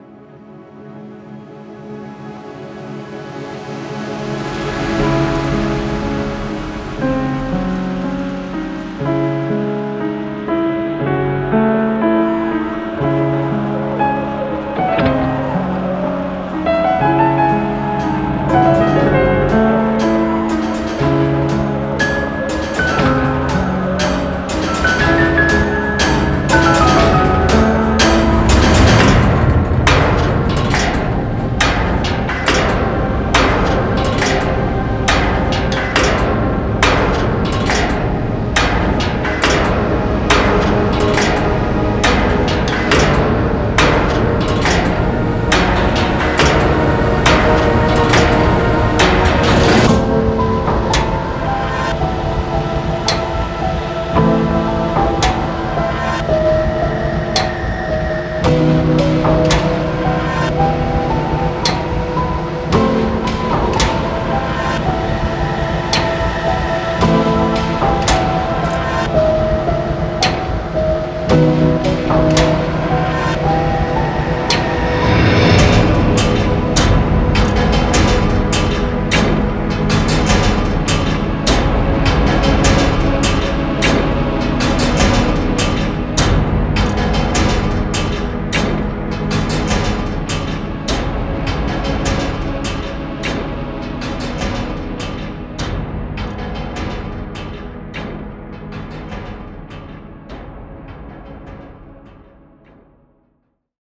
Filmscore
All sounds printed to Betamax tapes.
·     18 Atmospheric and Pad Loops
·     49 Industrial Metallic Loops